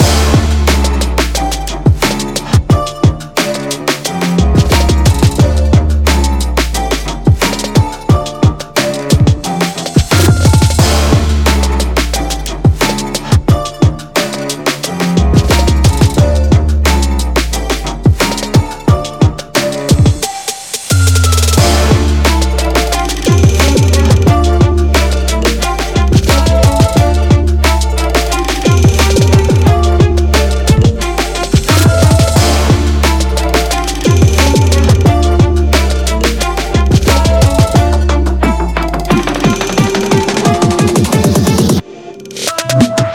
EDM/TRAPの各ループ素材はリズム音源トラックをメインに、各種TRAP系のシンセ音源などでミックス。
EDM/TRAP（トラップ）のミックス・ビート・トラックのループ素材です。